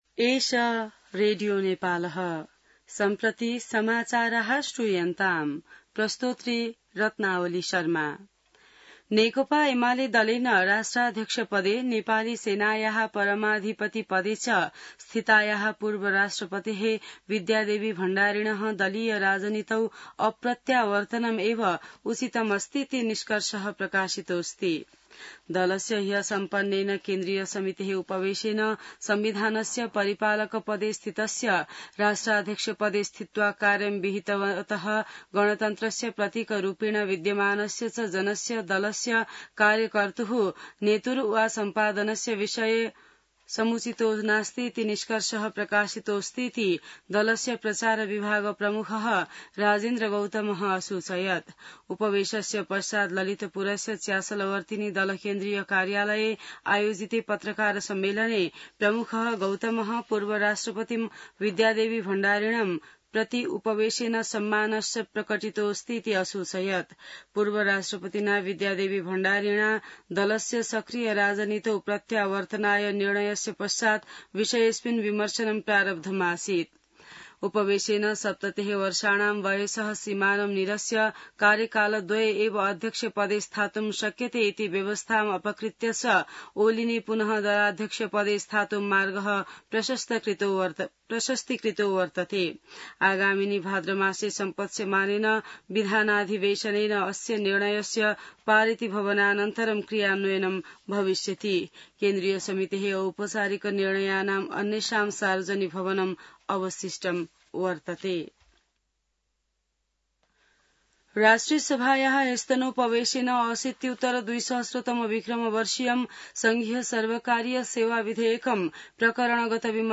संस्कृत समाचार : ७ साउन , २०८२